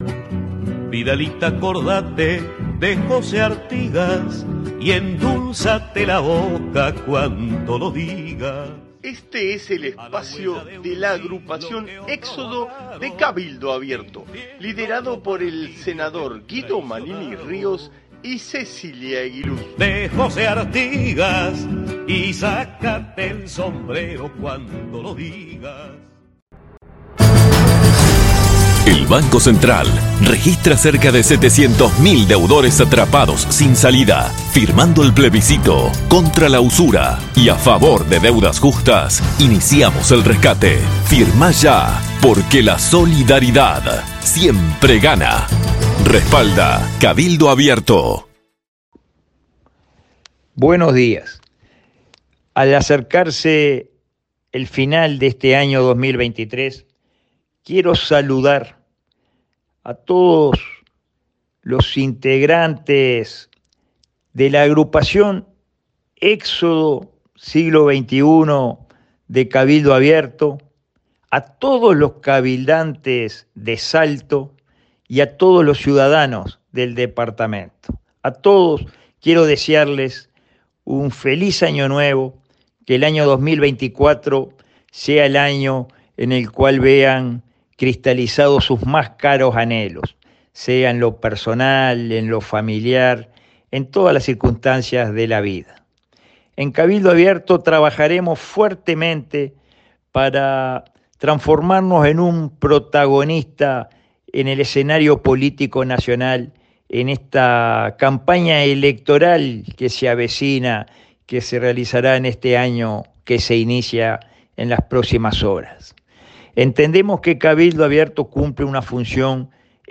Audición radial de nuestra agrupación para Radio Salto(1120AM) del día 28 de diciembre de 2023. A cargo nuestro líder y principal referente Nacional, el Senador Guido Manini Ríos.